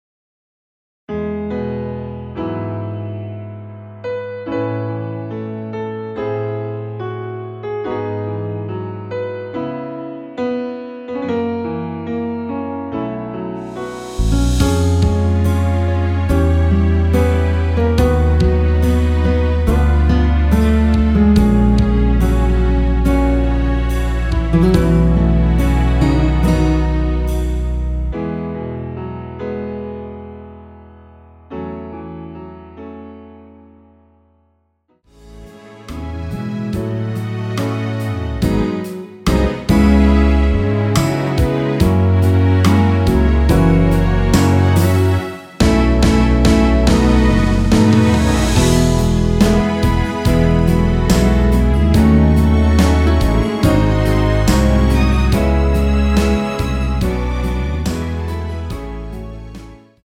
MR 입니다.
원키에서(-1)내릴ㄴ MR입니다.
◈ 곡명 옆 (-1)은 반음 내림, (+1)은 반음 올림 입니다.
앞부분30초, 뒷부분30초씩 편집해서 올려 드리고 있습니다.
중간에 음이 끈어지고 다시 나오는 이유는